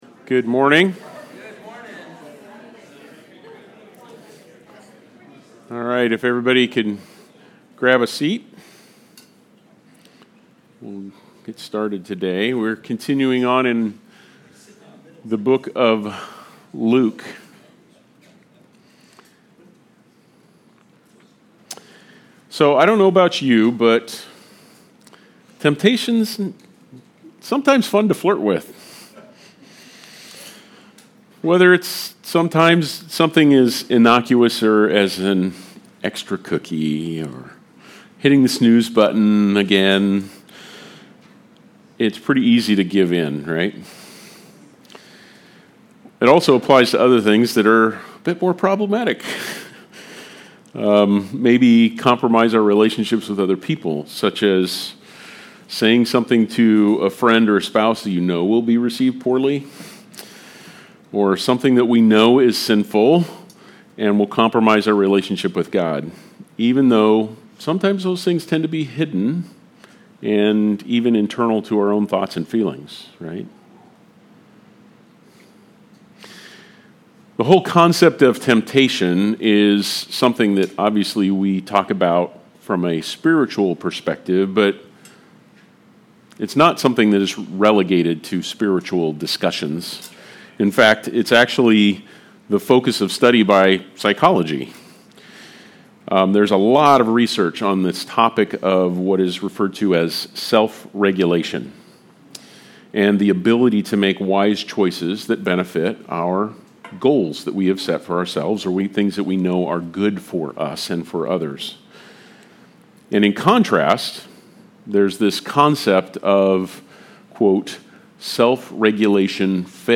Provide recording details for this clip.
Luke 4:1-13 Service Type: Sunday Service Related « Gemstones of Jesus’ Genealogy